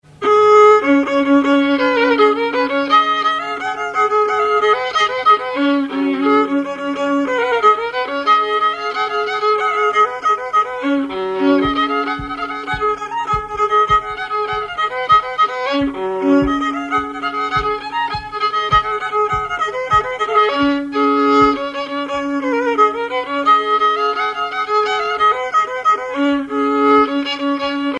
Rigodon au violon La débraillée
musique traditionnelle ; violoneux, violon,
danse : rigaudon